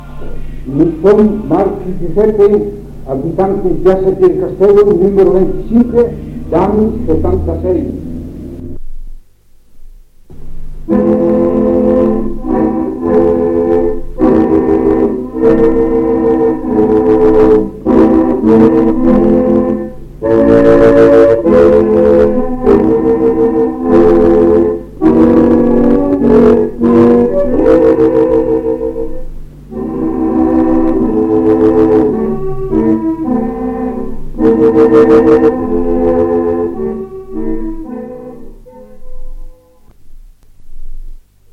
Post 1975. 1 bobina di nastro magnetico.
Serbi Dio l’austriaco Regno (esecuzione all’armonium ‘uso Trento’